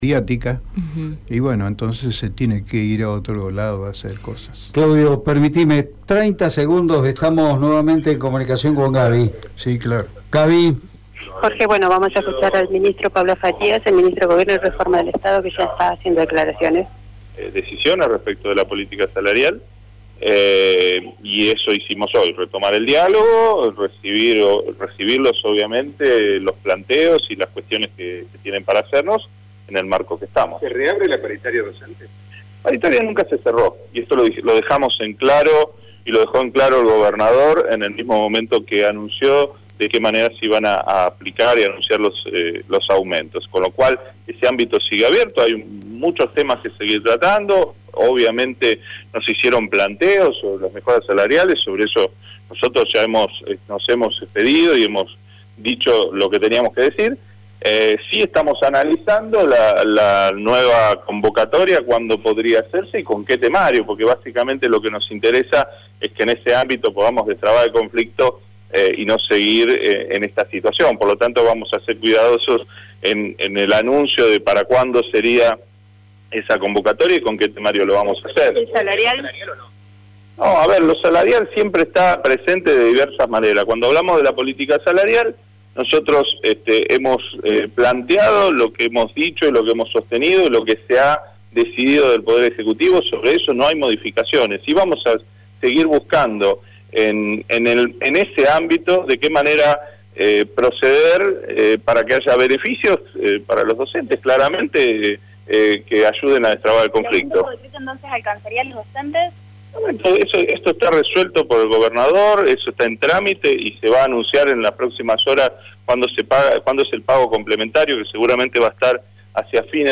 Mientras que del lado del Estado habló Pablo Farias, ministro de Gobierno de la Provincia de Santa Fe.